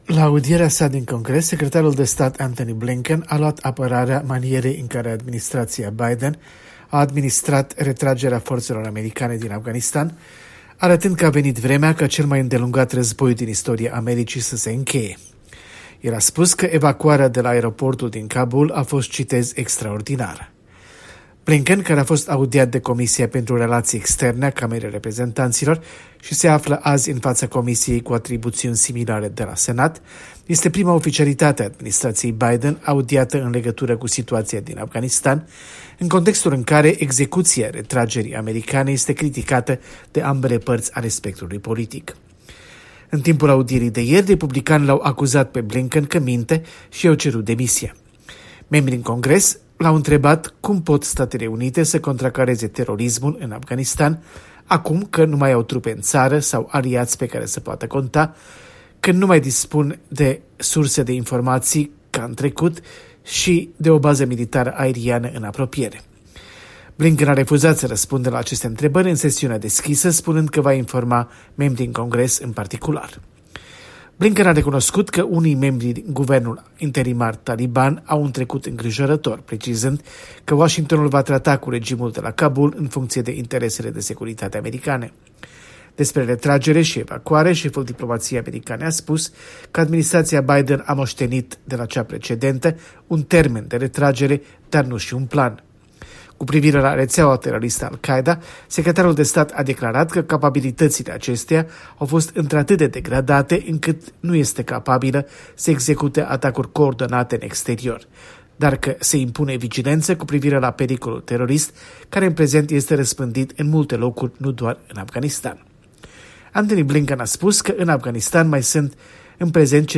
Corespondența de la Washington